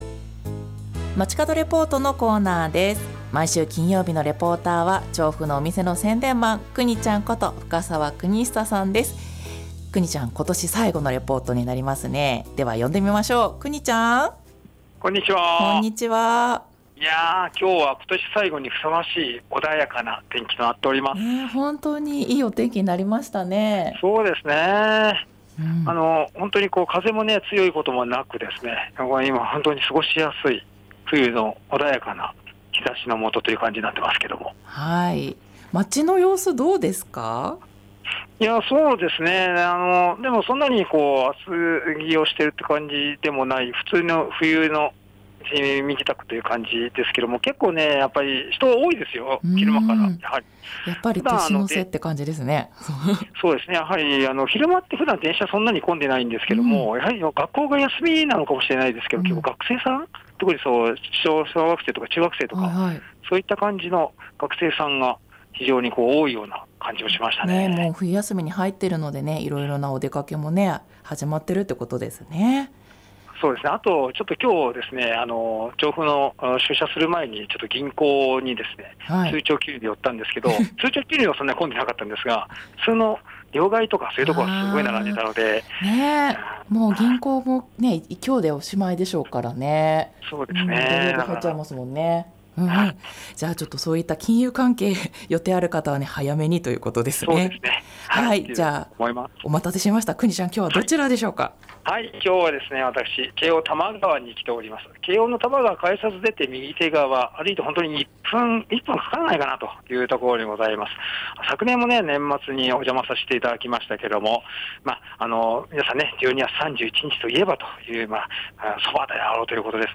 午後のカフェテラス 街角レポート
今年最後のレポートは京王多摩川に出没です 駅から歩いて1分 そば田麦山さんにお邪魔しました。